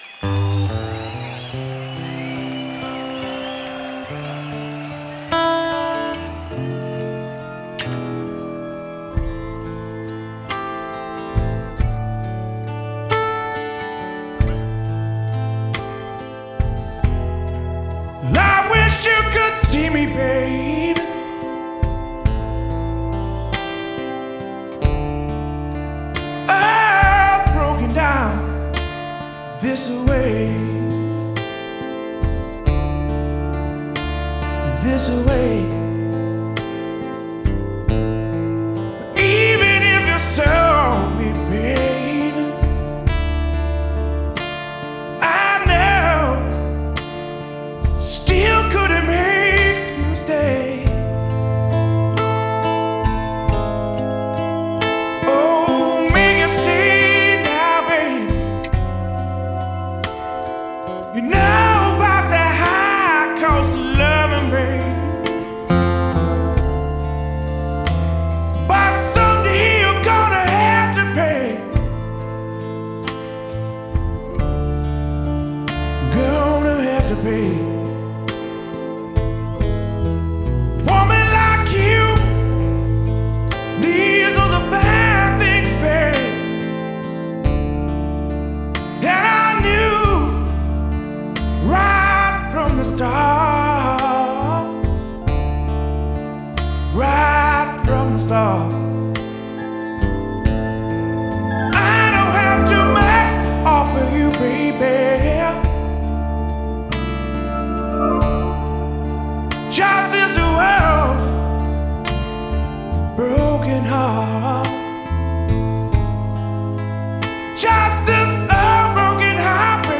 концертная запись